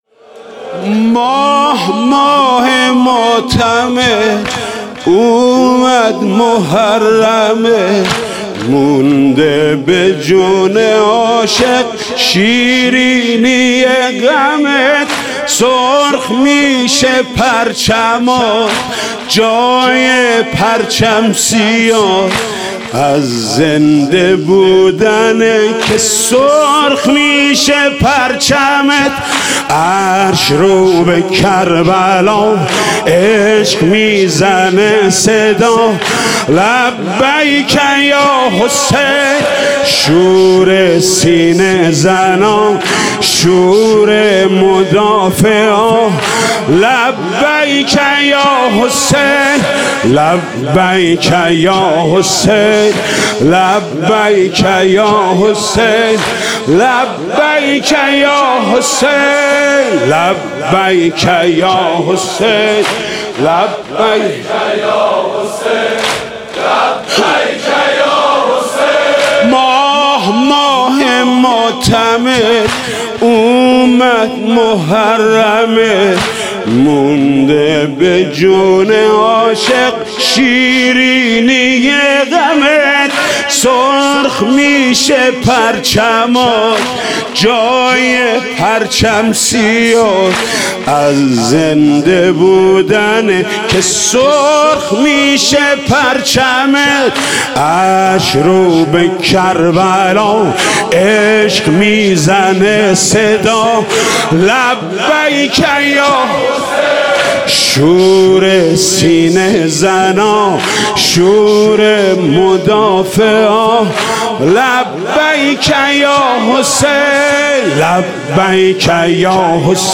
شب اول محرم - به نام نامی حضرت مسلم(ع)
حاج محمود کریمی
محمود کریمی مداحی شب اول واحد شب اول واحد